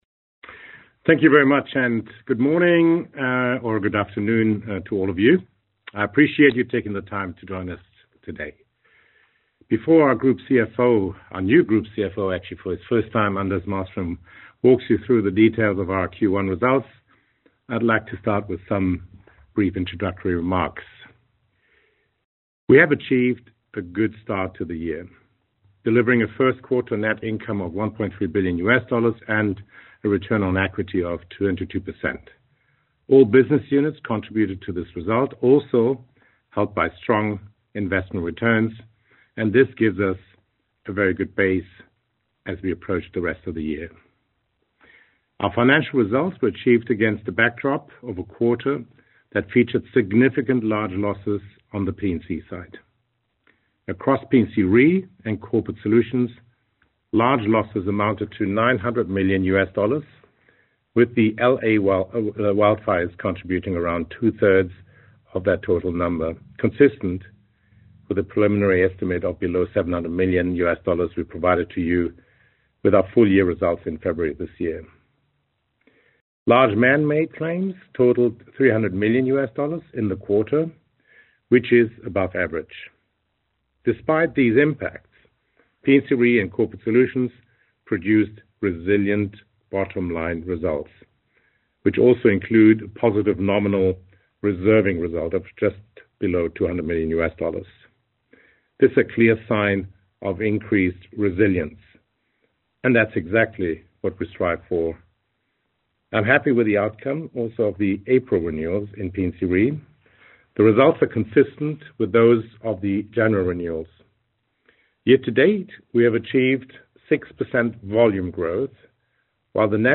Investor and Analyst Q&A (audio)